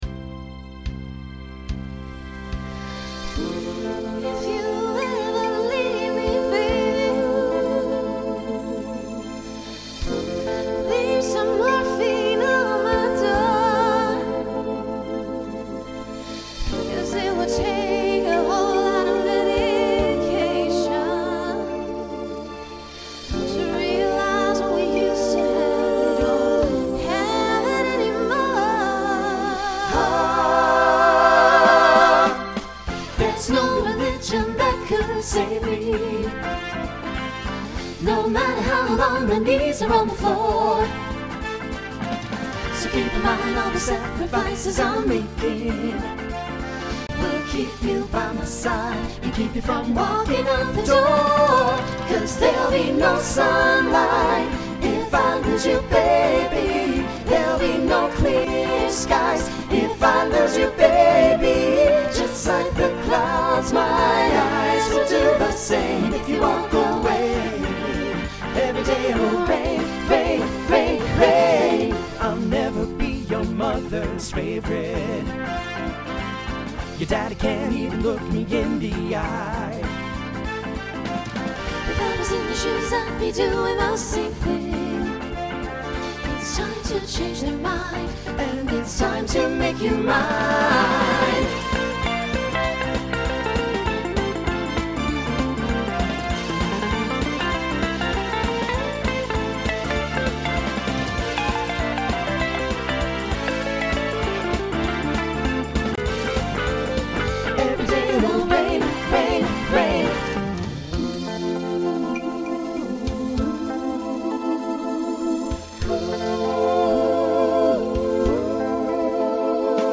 Voicing SATB Instrumental combo Genre Pop/Dance , Swing/Jazz
Mid-tempo